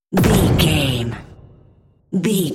Dramatic hit slam door rvrb
Sound Effects
heavy
intense
dark
aggressive
hits